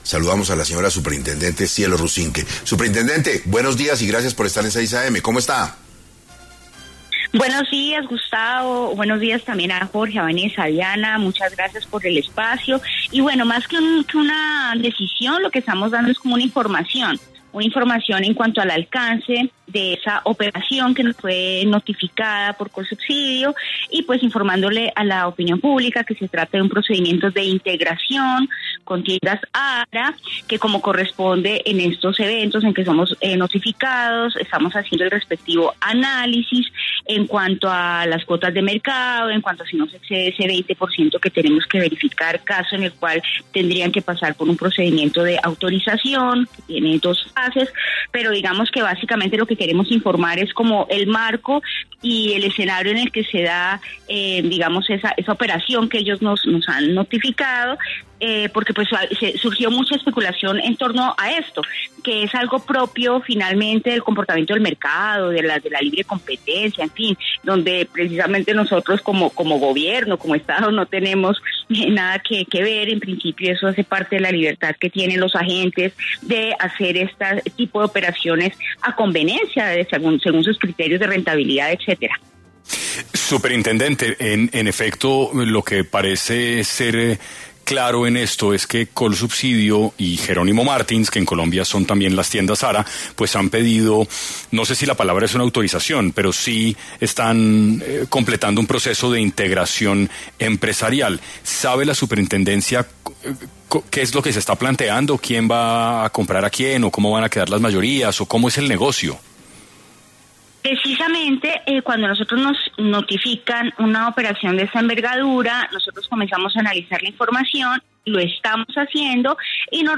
En 6AM de Caracol Radio estuvo Cielo Rusinque, Superintendente de Industria y Comercio, para hablar sobre el cierre de los supermercados Colsubisidio y que viene tras esta decisión.